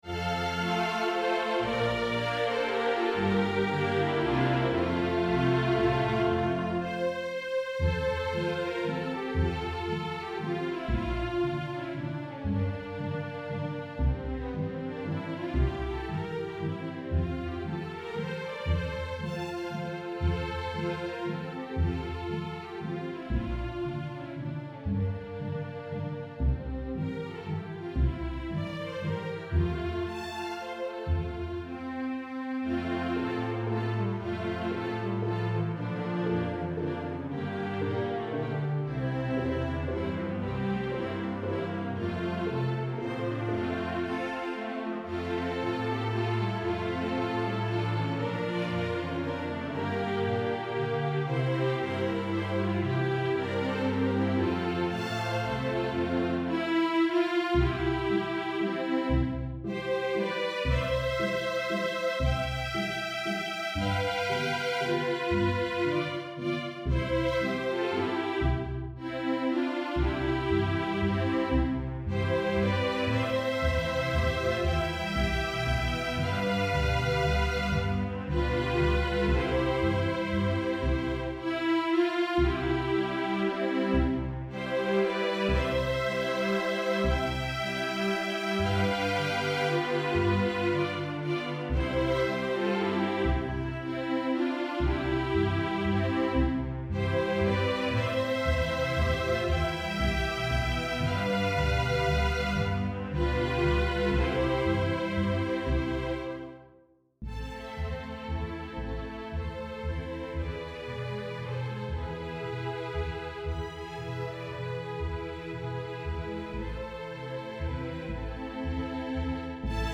INTERMEDIATE, STRING QUARTET
Notes: swing eighths
1st Violin 3rd position